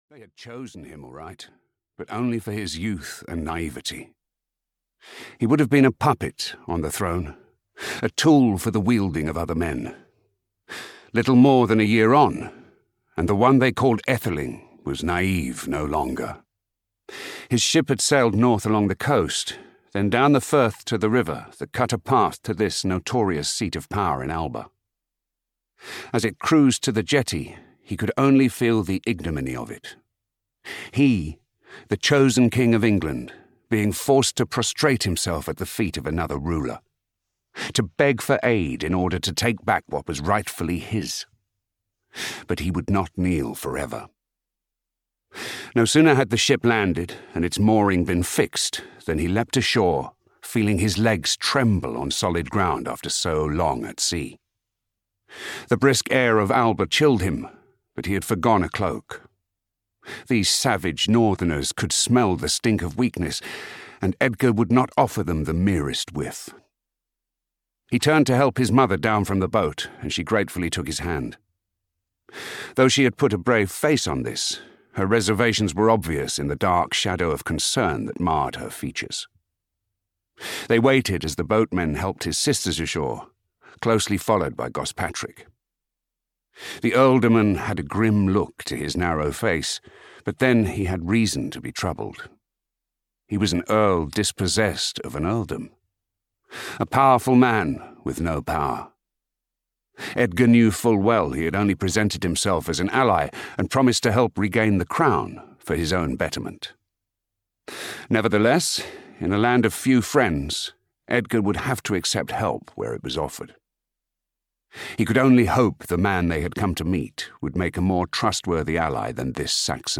Shield Breaker (EN) audiokniha
Ukázka z knihy